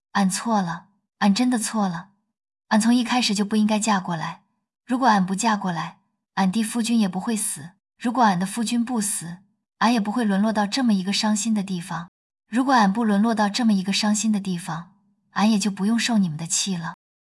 其合成音频中，不仅复刻了甄嬛的声线，语气也十分平缓，诉说过程衔接自然流畅。
这些音频的实现效果，有的仅需要原角色不到5s的音频就能实现，且可以看出上面几段音频都没有出现明显的卡顿，还复刻了声调上扬、语速、说话节奏等细微的特征。